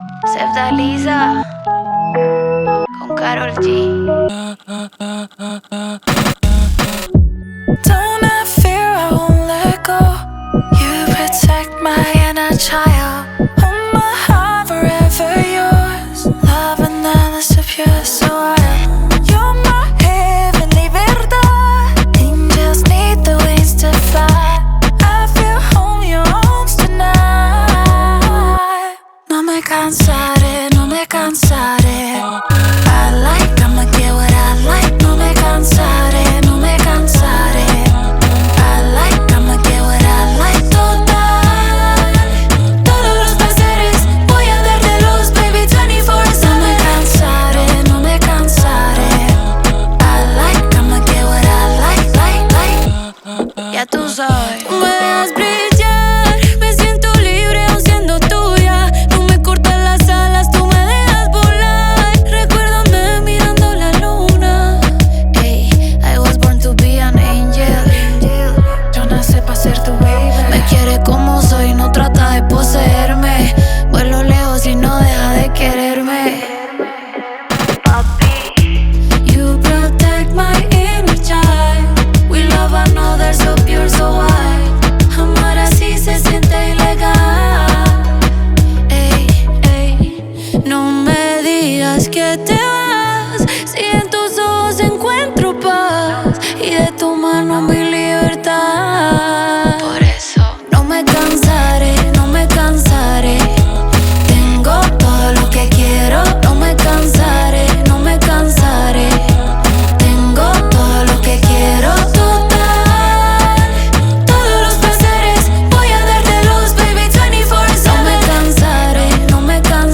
سبک لاتین
لاتینا